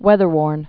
(wĕthər-wôrn)